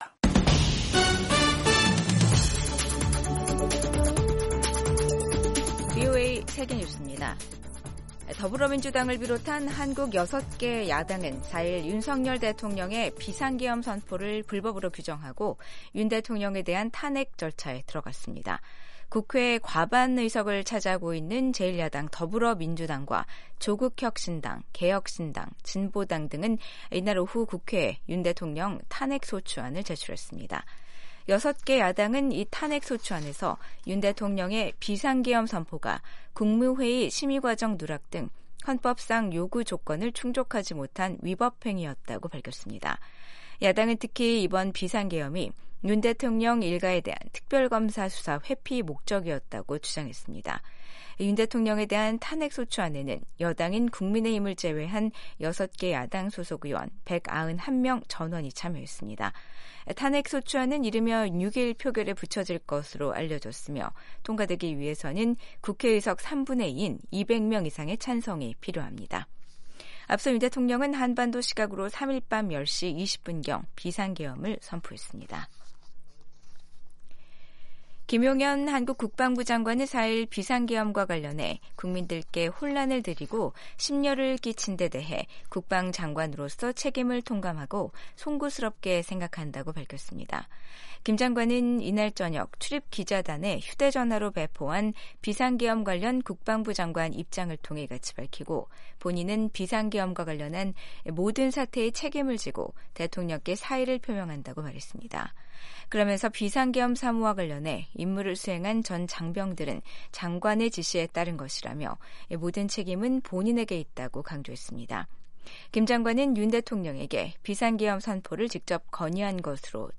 세계 뉴스와 함께 미국의 모든 것을 소개하는 '생방송 여기는 워싱턴입니다', 2024년 12월 5일 아침 방송입니다. 한국의 윤석열 대통령이 3일 비상계엄을 선포했지만, 몇 시간 뒤 국회 요구에 따라 해제했습니다. 한국 6개 야당이 윤석열 대통령 탄핵소추안을 국회에 제출했습니다. 한국의 급작스러운 정국 혼란에 미국을 비롯한 국제 사회는 일제히 우려를 표하며 사태를 예의 주시하고 있다고 밝혔습니다.